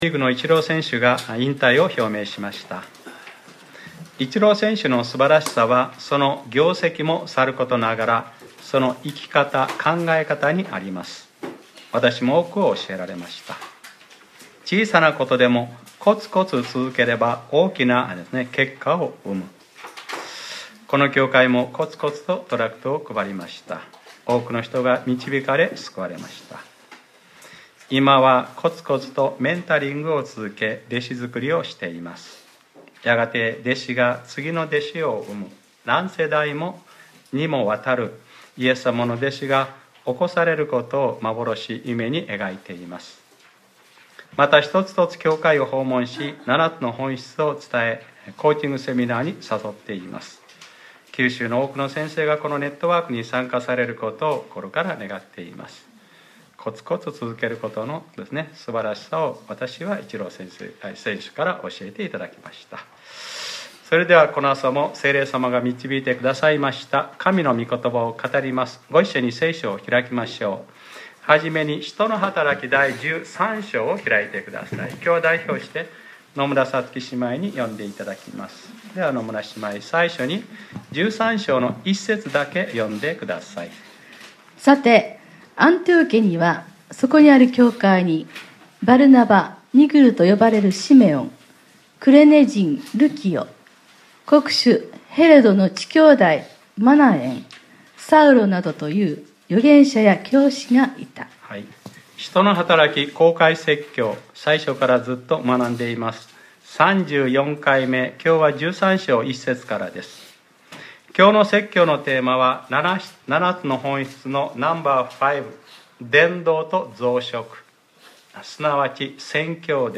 2019年03月24日（日）礼拝説教『わたしが召した任務につかせなさい』